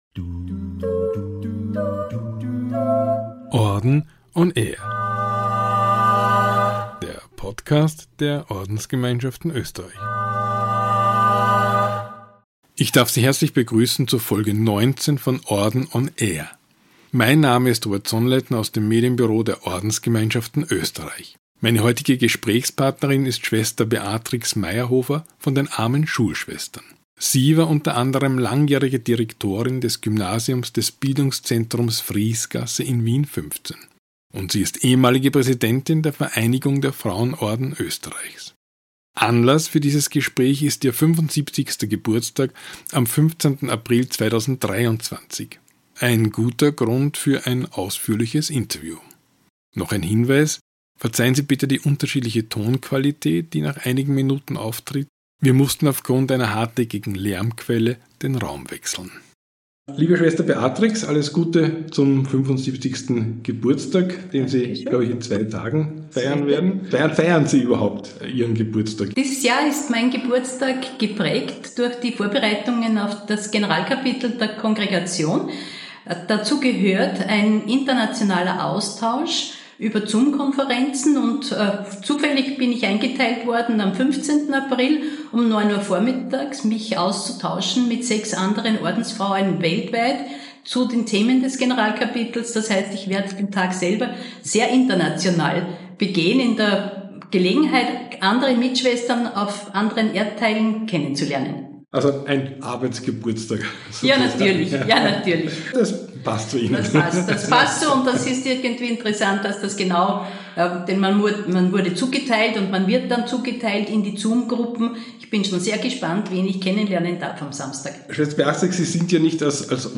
unser Gast vor dem Mikrofon